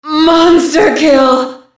Index of /cstrike/sounds/quake/female